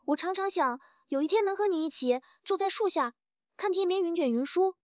プロフェッショナルなレストランプロモーション動画ボイス
高級レストランのコマーシャル、ソーシャルメディアプロモーション、ブランドストーリーテリングのためにデザインされた、洗練された食欲をそそるAIボイスで、食事客を魅了しましょう。
コマーシャルボイスオーバー
食欲をそそるトーン